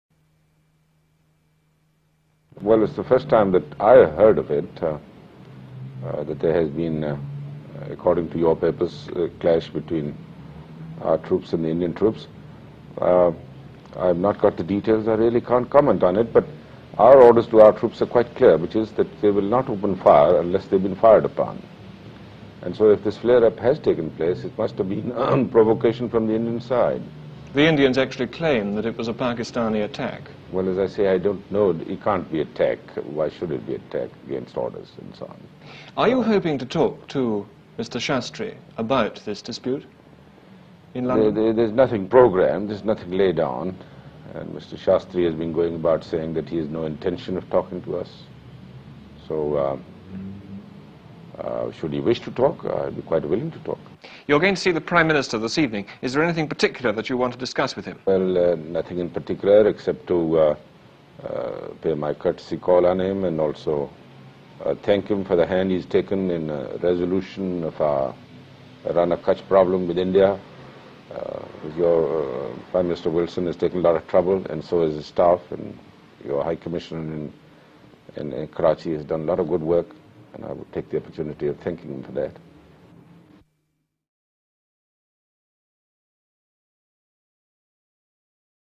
16 June 1965 President Ayoub Khan gives interview in London about the ongoing Rann of Kutch dispute in Pak-India border.